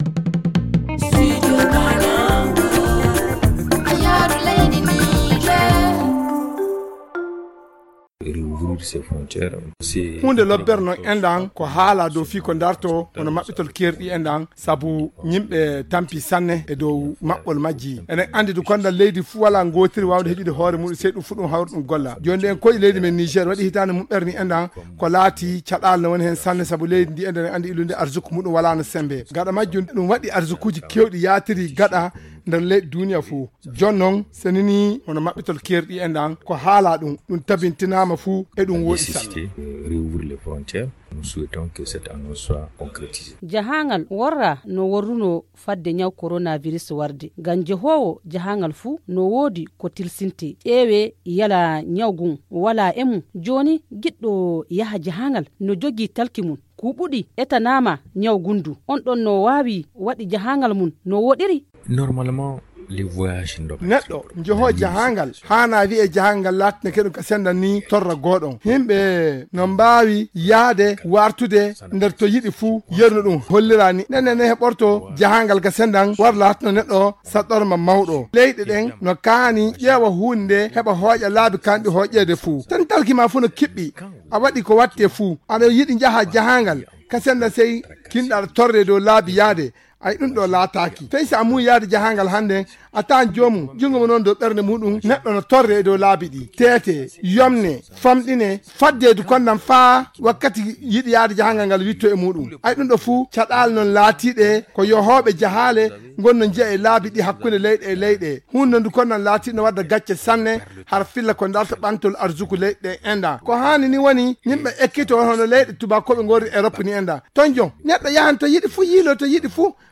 Le magazine en fulfuldé